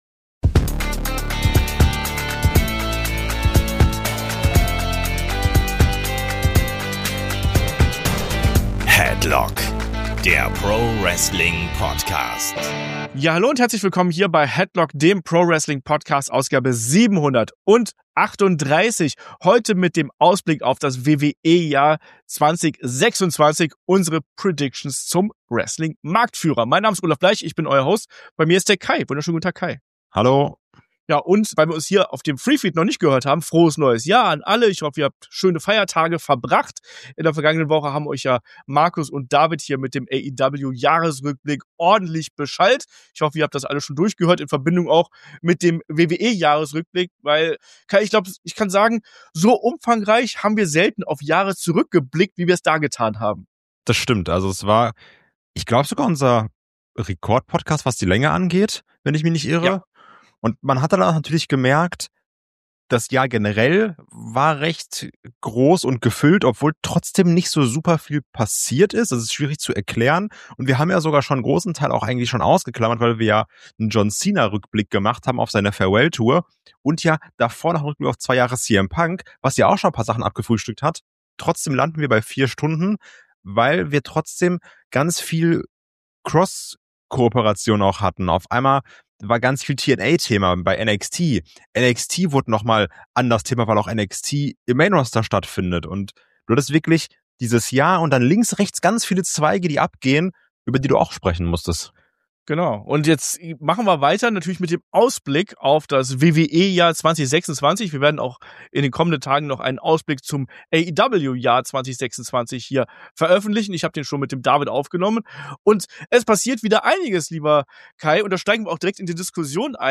Zum Abschluss setzt es noch die Schnellfeuerrunde u.a. mit Prognosen über eine Rückkehr von Vince McMahon oder auch The Rock. Hinweis: Leider gab es während der Aufnahme technische Probleme, wodurch die Episode kürzer als geplant wurde.